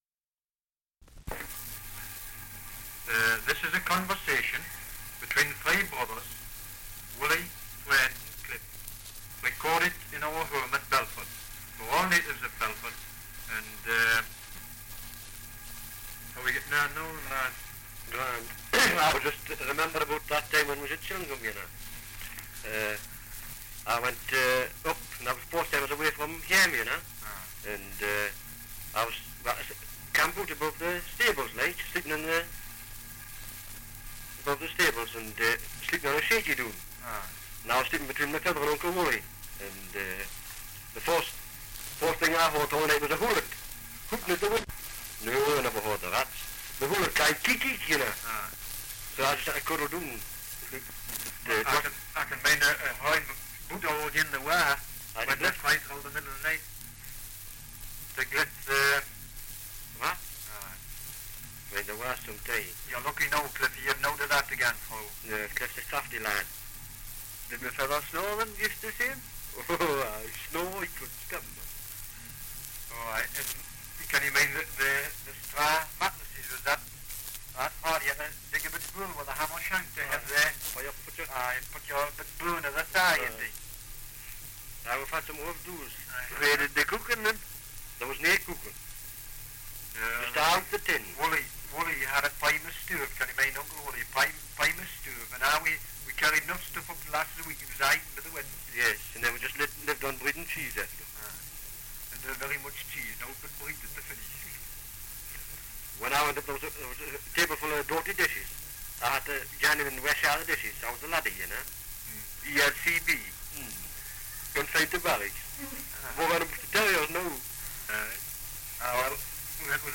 Dialect recording in Belford, Northumberland
78 r.p.m., cellulose nitrate on aluminium